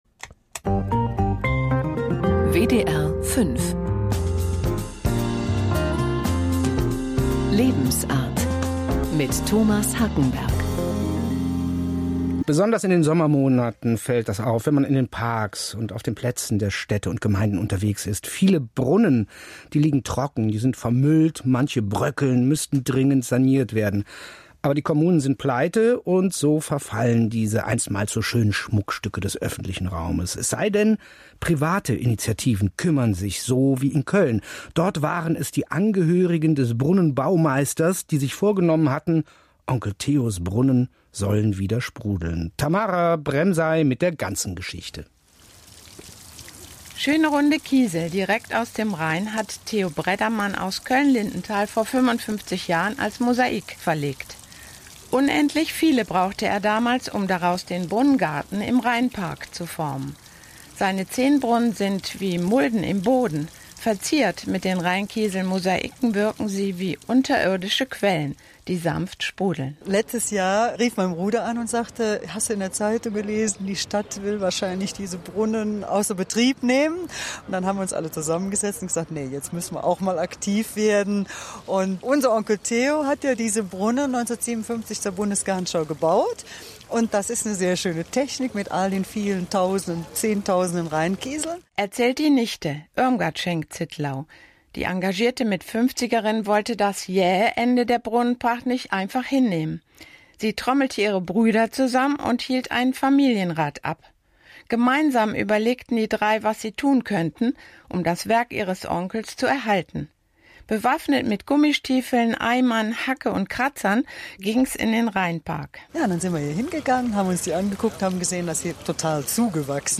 Unser erster Radiobeitrag: